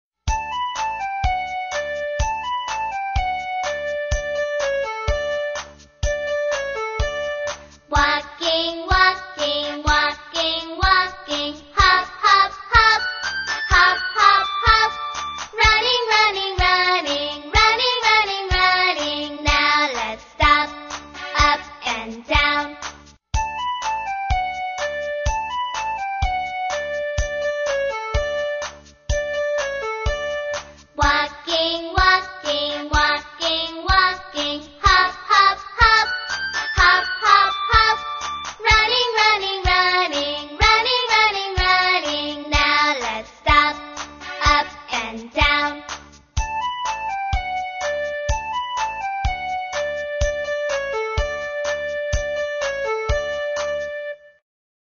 在线英语听力室英语儿歌274首 第243期:Walking,Walking的听力文件下载,收录了274首发音地道纯正，音乐节奏活泼动人的英文儿歌，从小培养对英语的爱好，为以后萌娃学习更多的英语知识，打下坚实的基础。